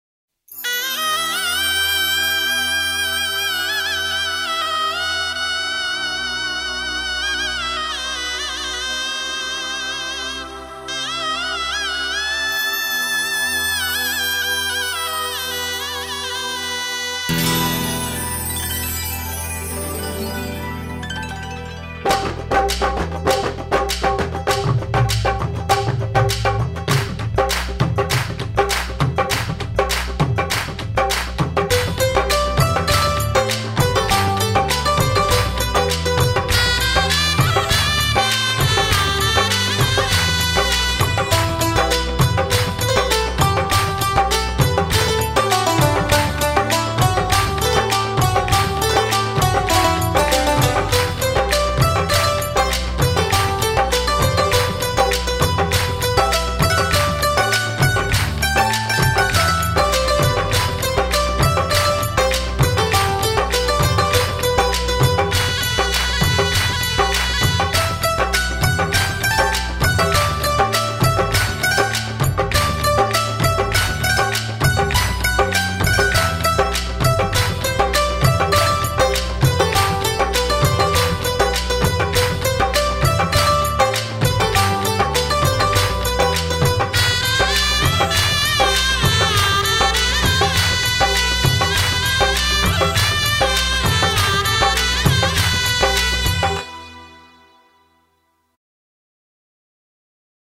[Artist: Instrumental ]